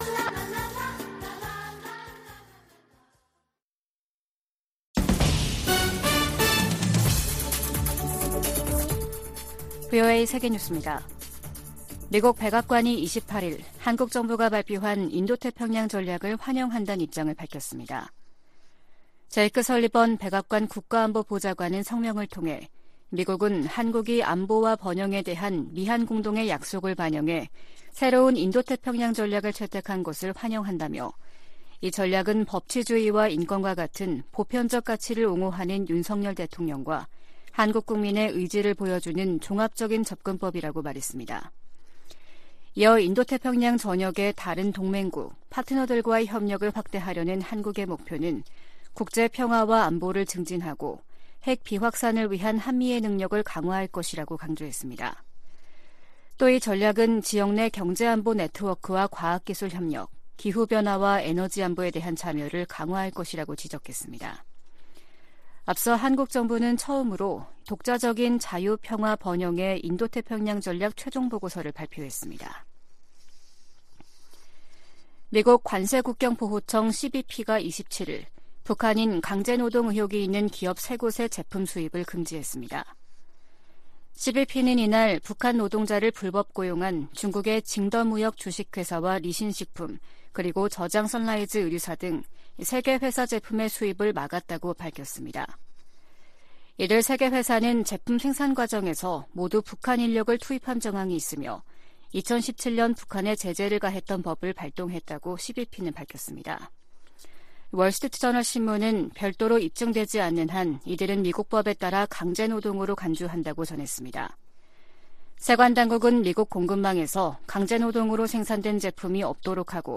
VOA 한국어 아침 뉴스 프로그램 '워싱턴 뉴스 광장', 2022년 12월 29일 방송입니다. 윤석열 한국 대통령은 북한의 드론 즉 무인기 도발을 계기로 강경 대응 의지를 연일 강조하고 있습니다. 유엔은 북한 무인기가 한국 영공을 침범한 것과 관련해 한반도의 긴장 고조에 대해 우려하고 있다는 기존 입장을 재확인했습니다.